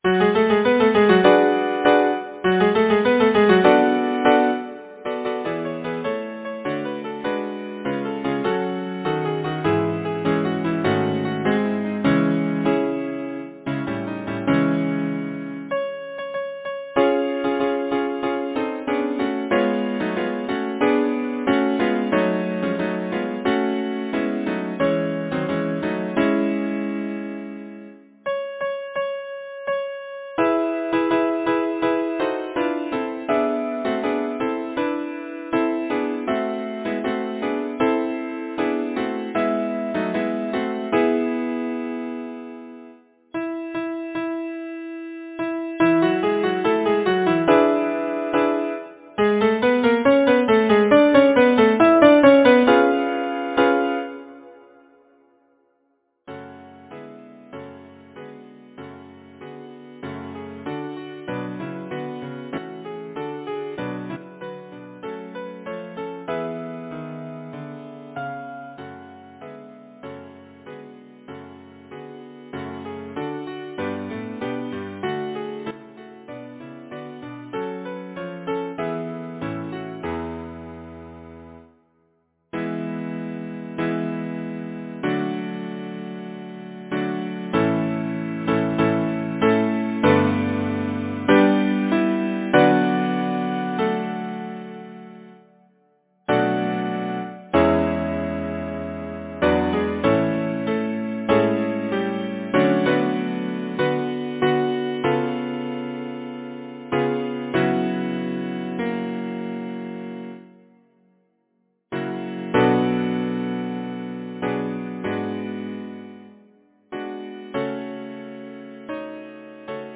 Number of voices: 4vv Voicing: SATB divisi Genre: Secular, Partsong
Language: English Instruments: A cappella